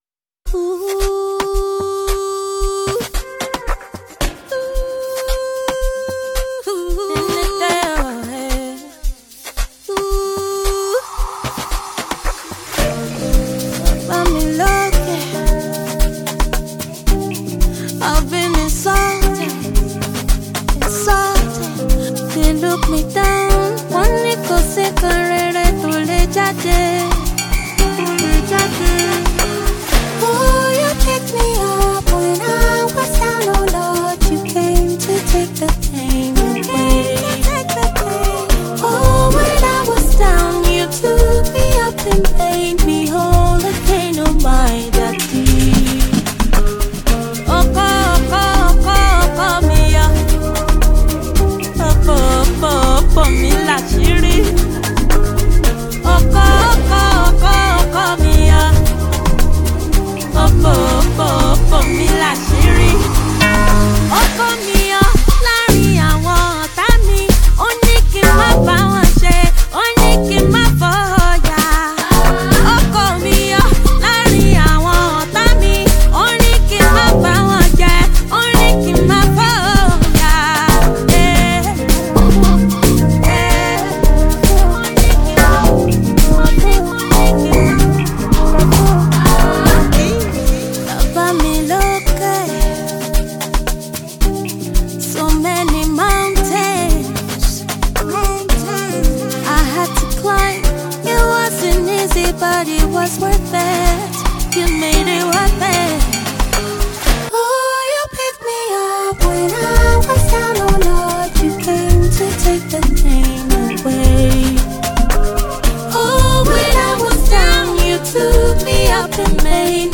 female, singer
an electrifying tune